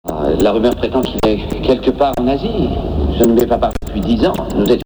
disque entiérement composé et mal  enregistré devant la télé